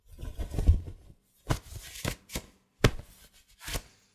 Cloth Whip Sound
household